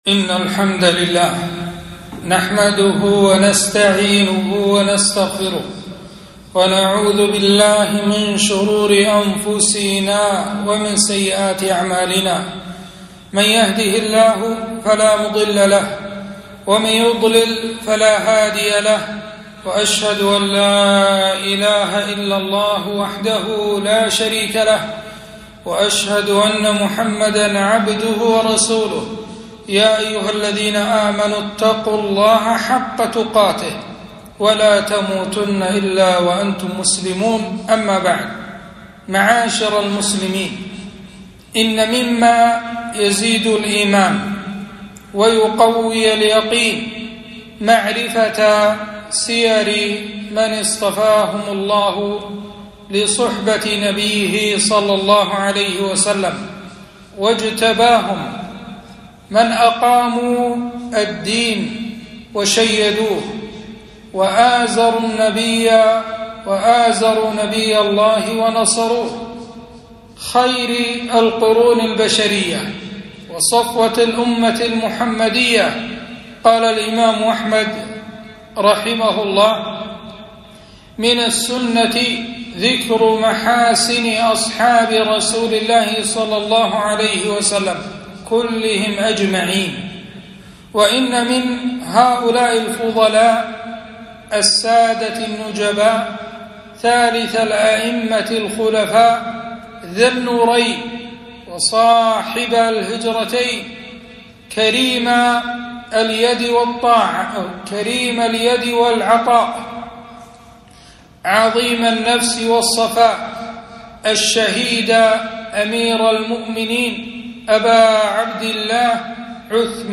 خطبة - فضائل عثمان بن عفان رضي الله عنه